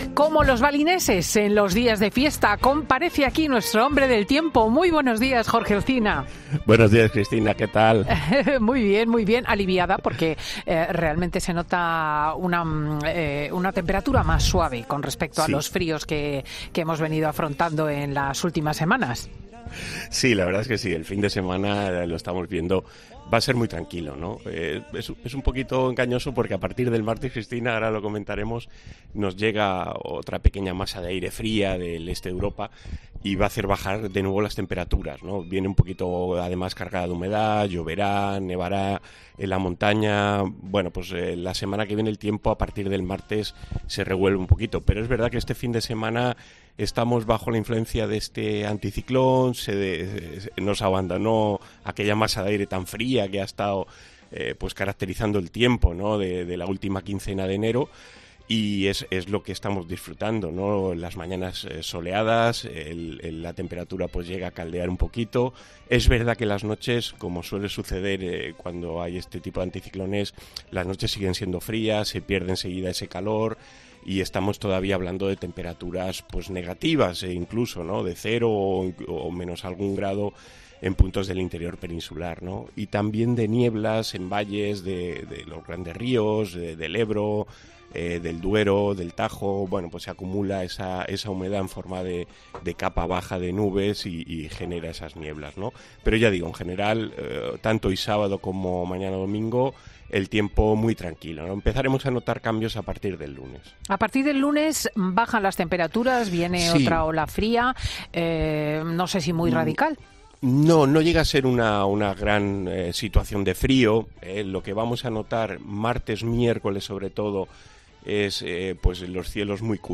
Un meteorólogo responde a la marmota Phil y su predicción para lo que queda de invierno: "En esta ocasión..."